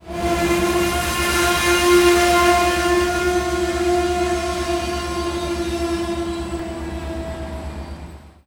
• train engine slow break and motor.wav
recorded with a Tascam DR40 in a country side train station, with an electrical locomotive.
train_engine_slow_break_and_motor_YnP.wav